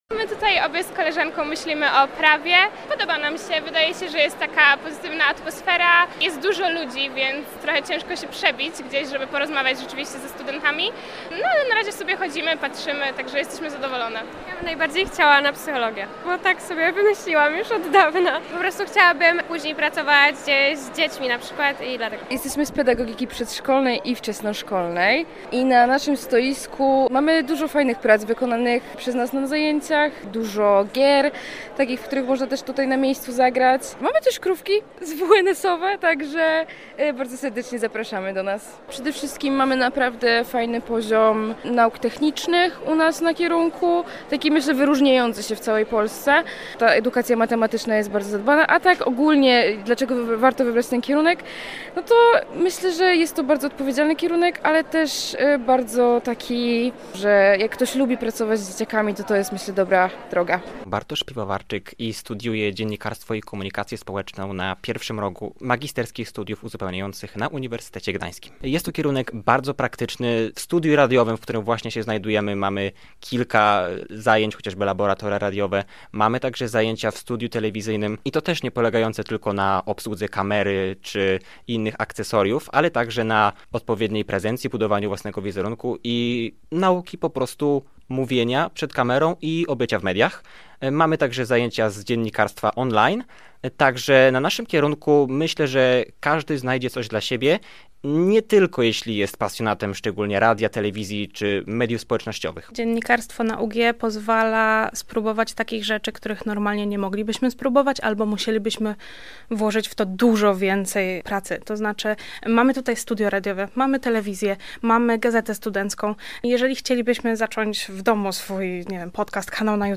Na miejscu obecne było też Radio Gdańsk i nasze mobilne studio.